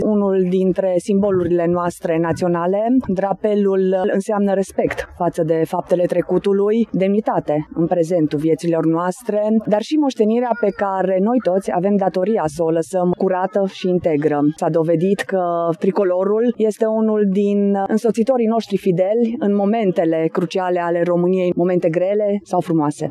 De Ziua Drapelului, celebrată pe 26 iunie, Garnizoana Târgu Mureş şi Instituţia Prefectului – Judeţul Mureş, cu sprijinul Primăriei municipiului Târgu Mureş și al Consiliulul Judeţean Mureş au organizat o ceremonie publică de înălţare a drapelului național al României pe catargul din Cetatea Medievală din Târgu Mureș.
Prefectul de Mureș, Mara Togănel a arătat, la rândul său, că drapelul a fost martorul celor mai importante momente ale istoriei acestui neam: